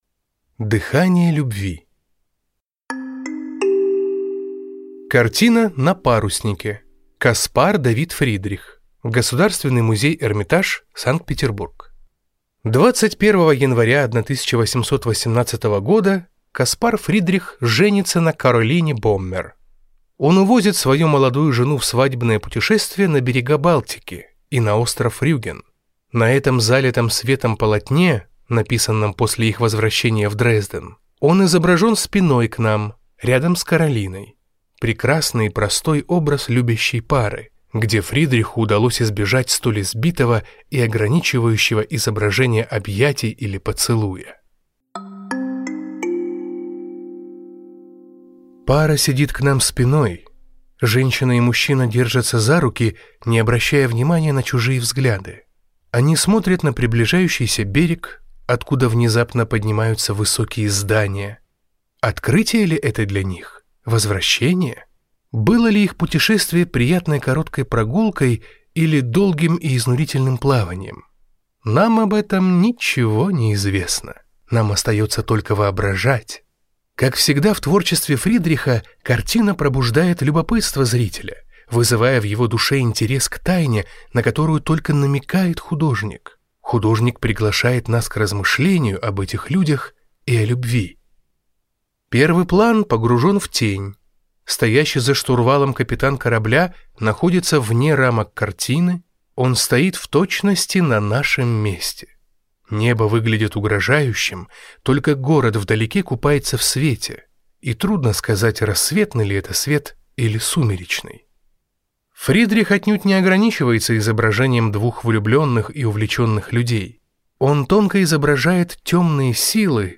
Аудиокнига Полдень. Полнота счастья | Библиотека аудиокниг
Прослушать и бесплатно скачать фрагмент аудиокниги